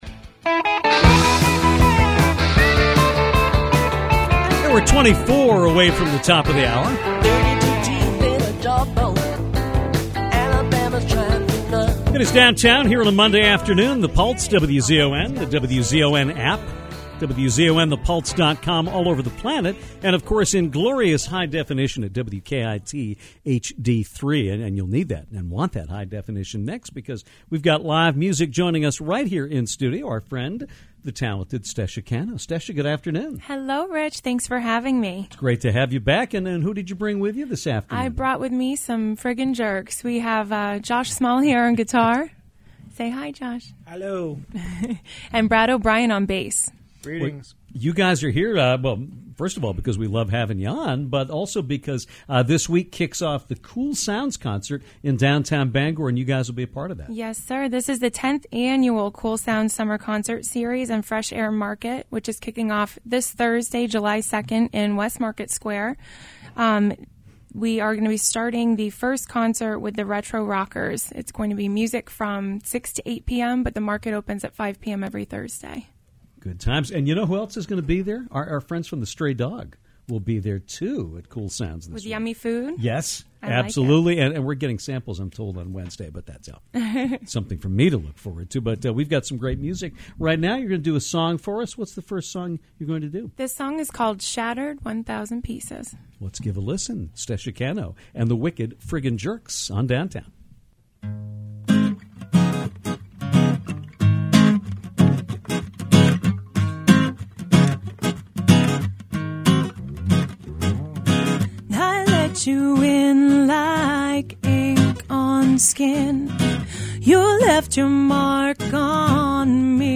Local singer and musician
played two songs in studio live as well.